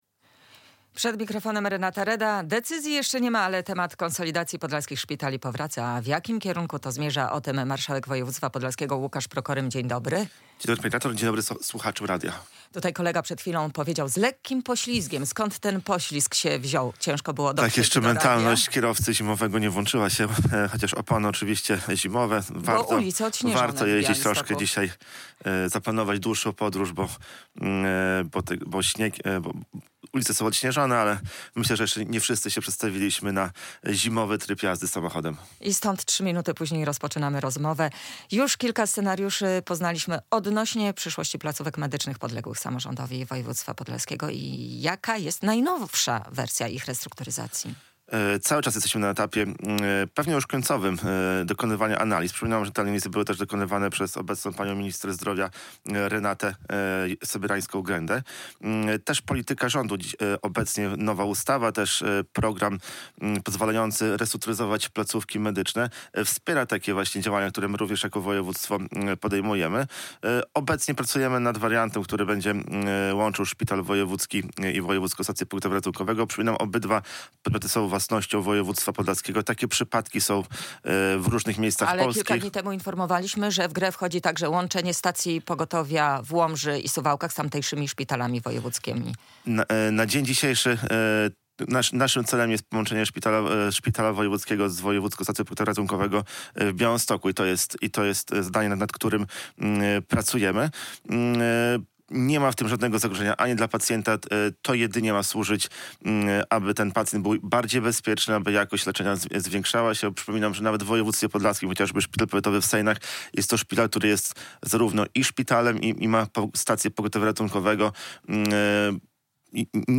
Łukasz Prokorym - Marszałek Województwa Podlaskiego
Radio Białystok | Gość | Łukasz Prokorym - Marszałek Województwa Podlaskiego